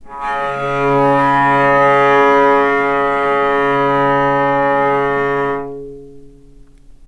vc_sp-C#3-mf.AIF